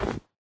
snow1.ogg